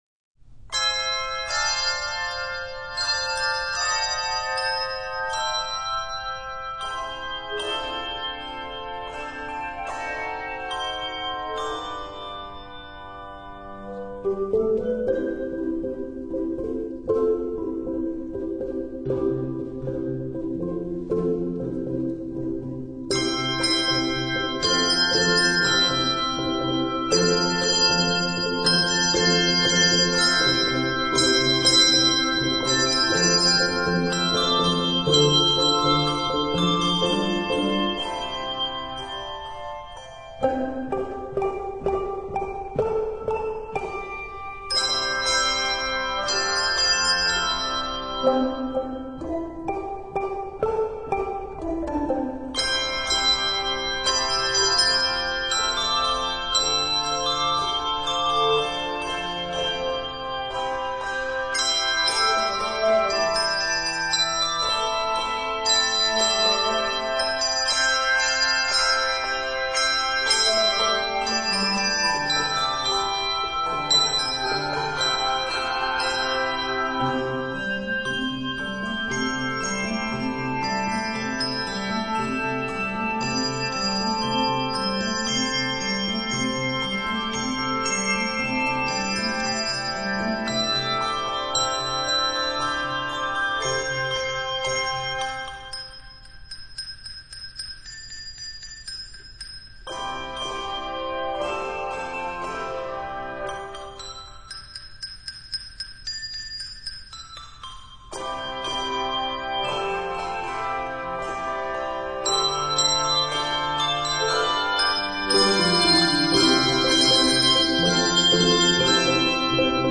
Composer: African American Spiritual
Voicing: Handbells